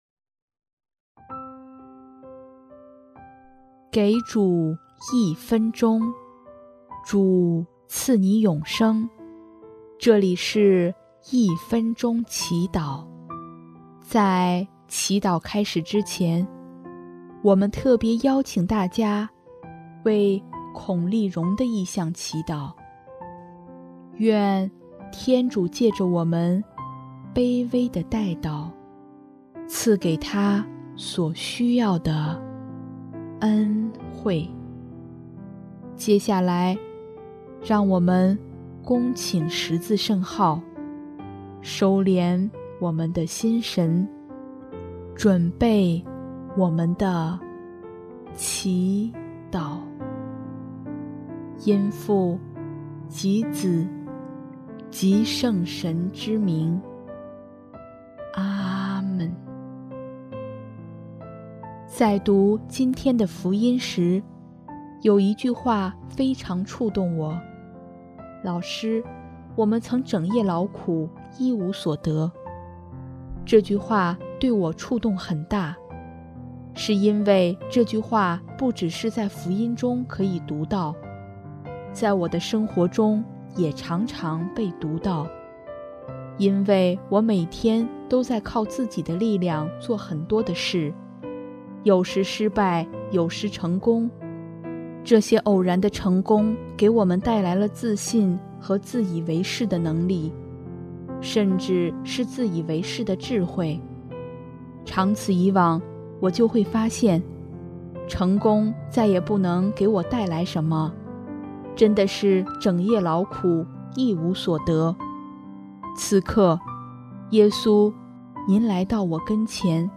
【一分钟祈祷】|9月5日 唯独依靠主